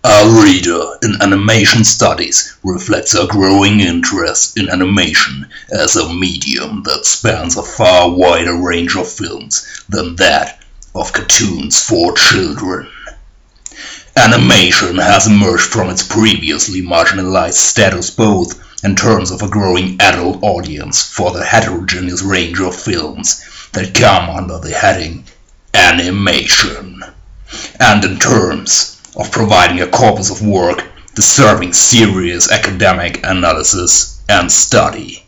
And now something completely different: A Dramatic Reading #1
Dramatic-Reading-1.wav